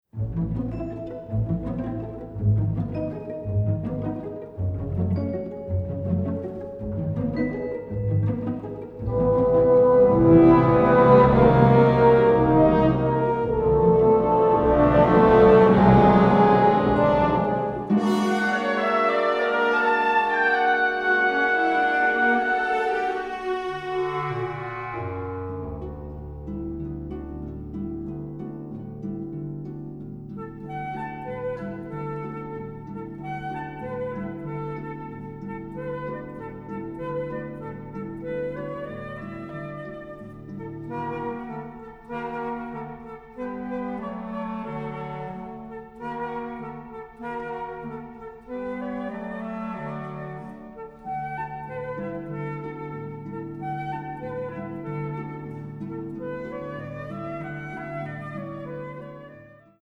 Recorded on the OSE orchestra stage in September 2018